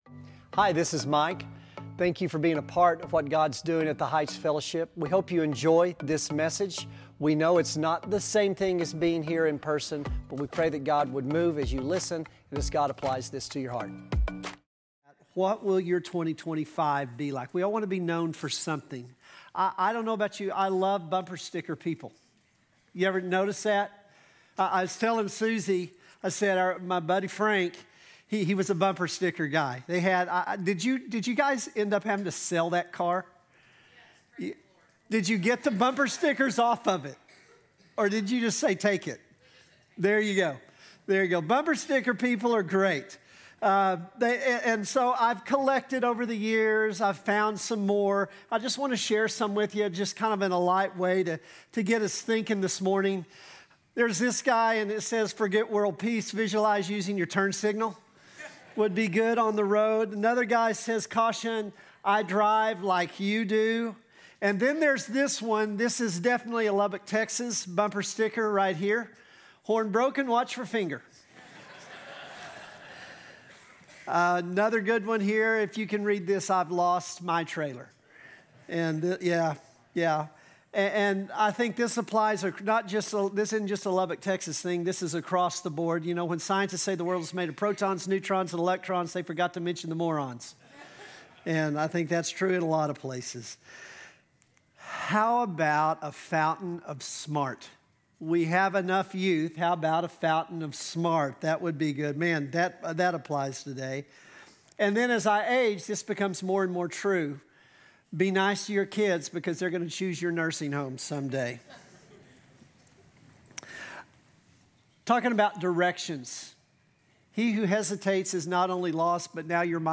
Announcements, news and weekly sermons from The Heights Fellowship church in Lubbock, Texas.